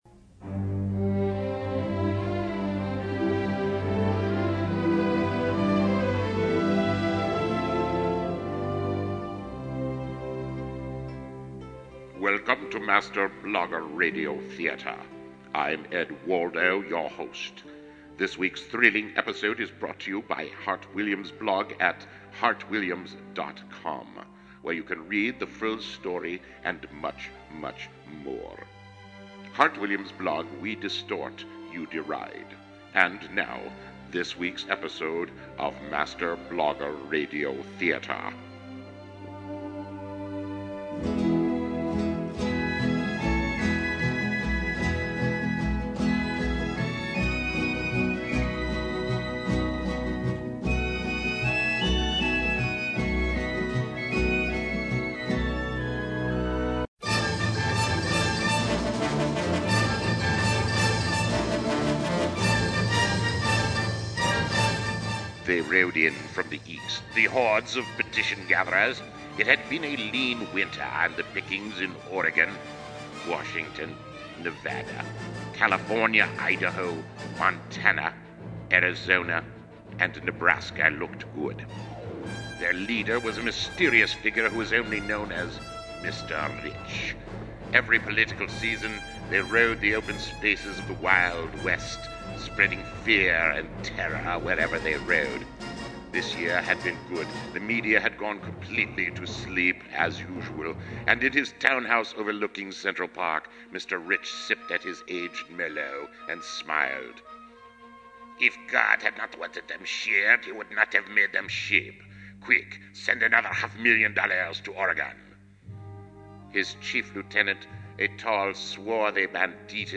MB Radio Theater humorous take on rich New Yorkers pushing ballot petitions 08-01-06
master blogger radio theater.mp3